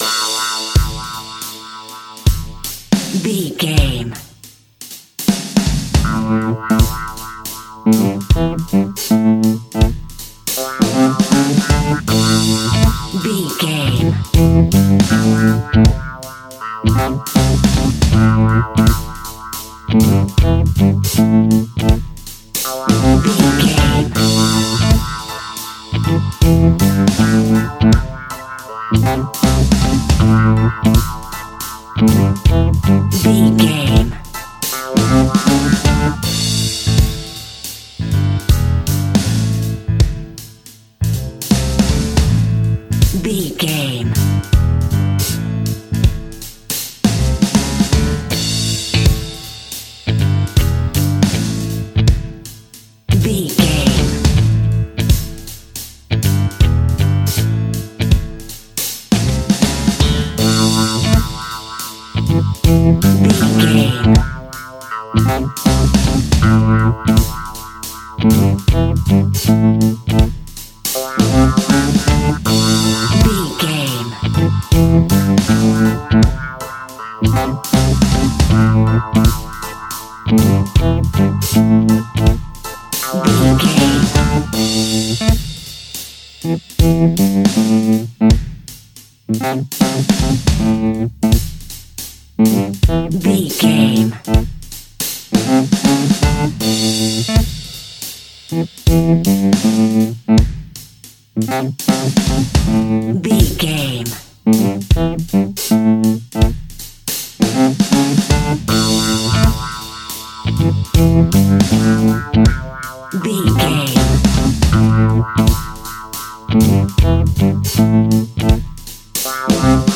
A hot sunshing BBQ with the backdrop of island reggae Music!
Aeolian/Minor
Slow
reggae instrumentals
laid back
chilled
off beat
drums
skank guitar
hammond organ
percussion
horns